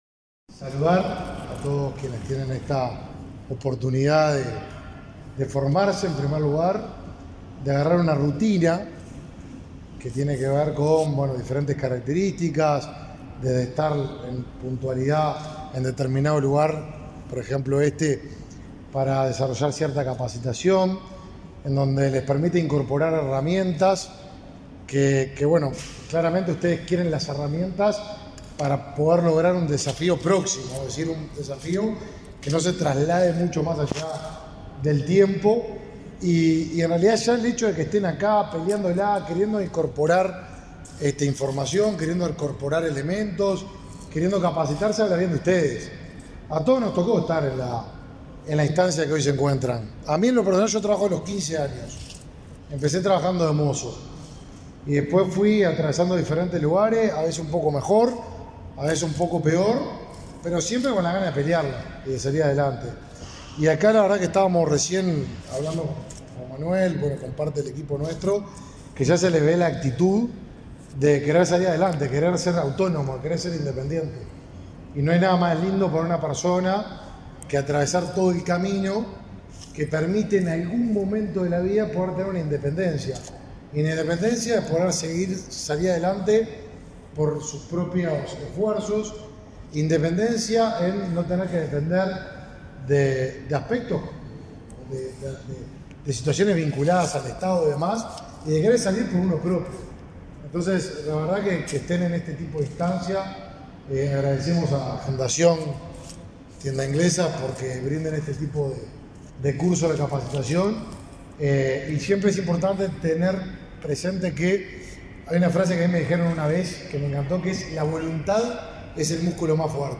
Declaraciones del ministro de Desarrollo Social, Martín Lema
El Ministerio de Desarrollo Social mantiene convenios laborales con empresas, con el fin de establecer la contratación de personas que participen en los programas sociales. Este 6 de setiembre, el ministro Lema visitó un taller en el que se capacita a los trabajadores para Tienda Inglesa, oportunidad en la que se expresó en conferencia de prensa.